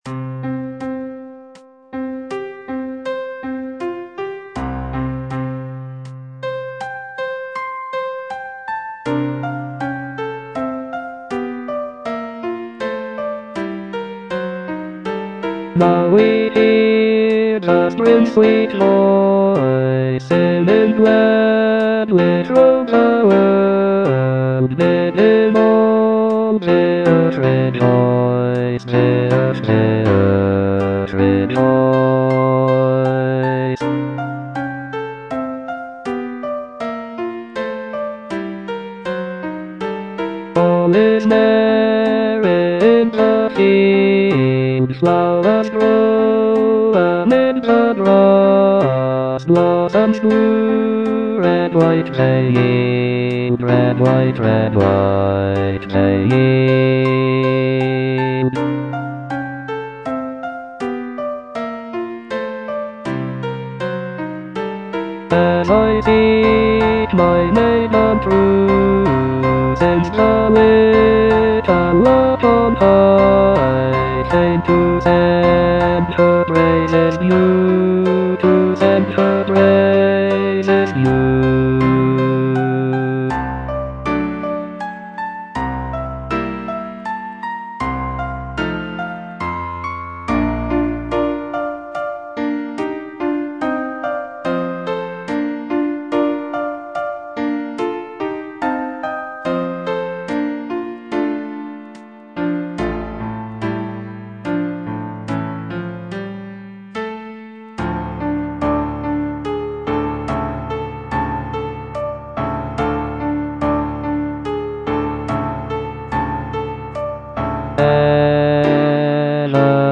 E. ELGAR - FROM THE BAVARIAN HIGHLANDS False love (bass I) (Voice with metronome) Ads stop: auto-stop